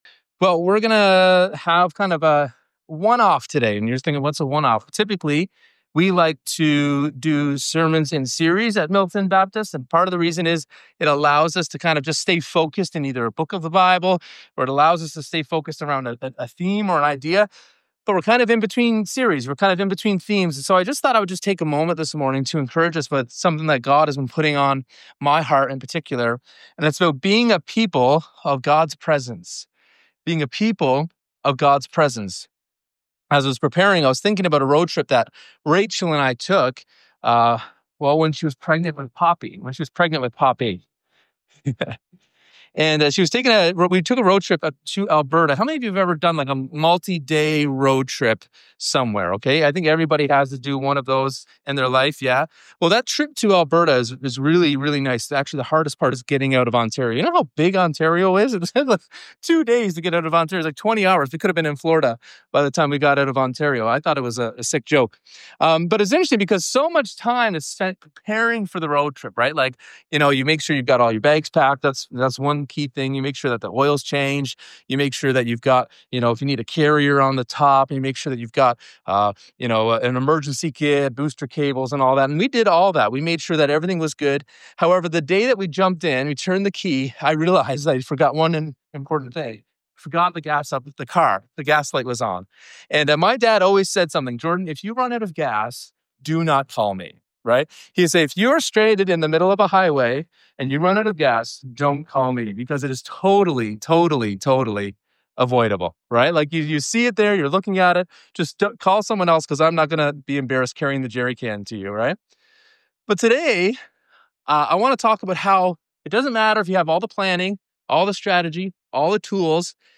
June 29 Sermon Final.mp3